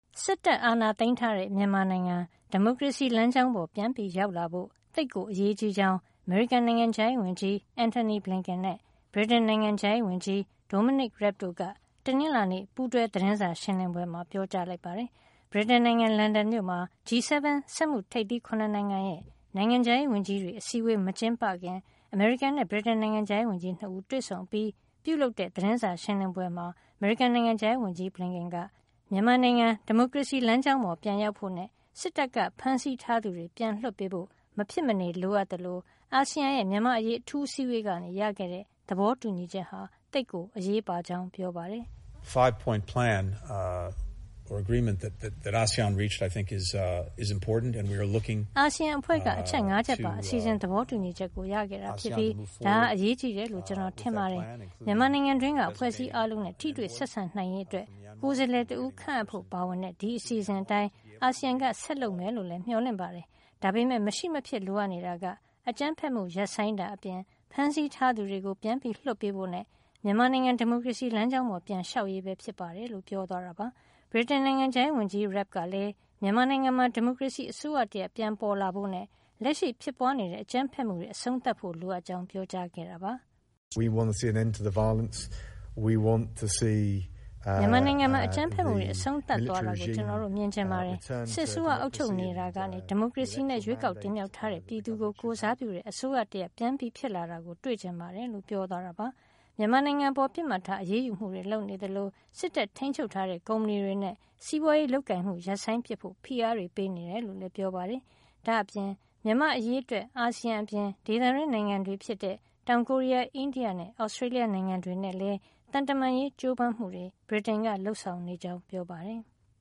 စစ်တပ်အာဏာသိမ်းထားတဲ့ မြန်မာနိုင်ငံ ဒီမိုကရေစီ လမ်းကြောင်းပေါ် ပြန်ရောက်လာဖို့ သိပ်ကိုအရေးကြီးကြောင်း အမေရိကန်နိုင်ငံခြားရေးဝန်ကြီး Antony Blinken နဲ့ ဗြိတိန်နိုင်ငံခြားရေးဝန်ကြီး Dominic Raab တို့က တနင်္လာနေ့ ပူးတွဲသတင်းစာရှင်းလင်းပွဲမှာ ပြောကြားလိုက်ပါတယ်။